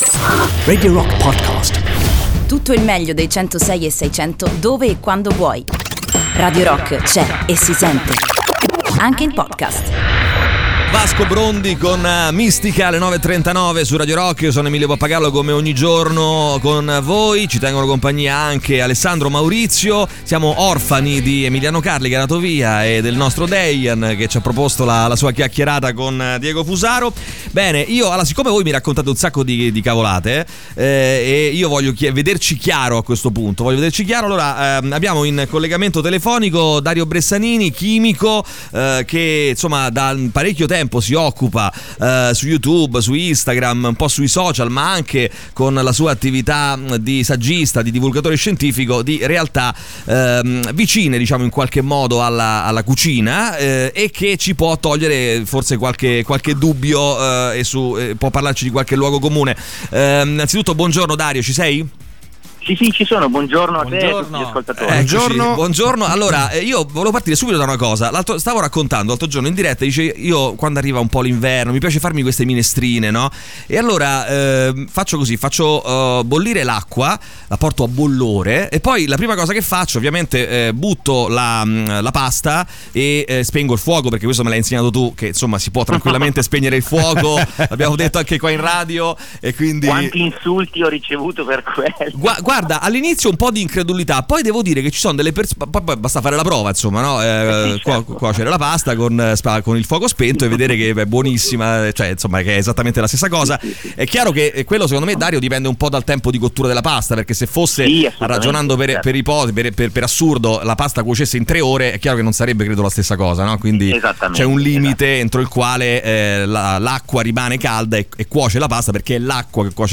Intervista: Dario Bressanini (17-10-18)